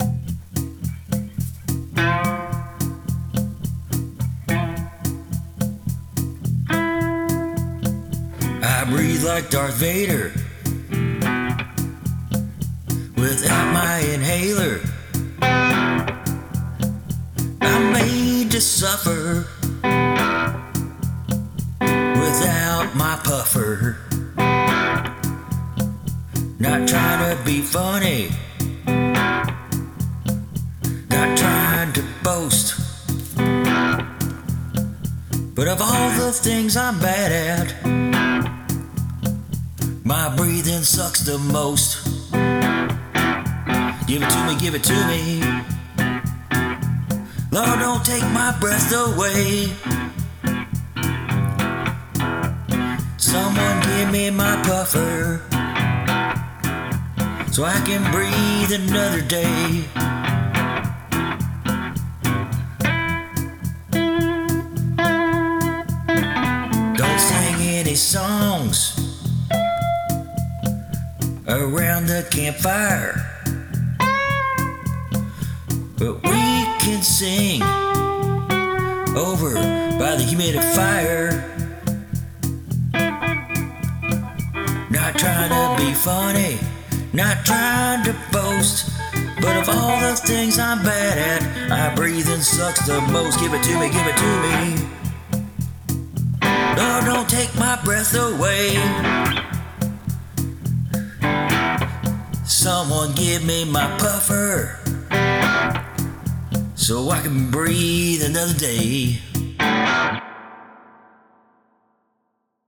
The kind of song old blues players oughta sing!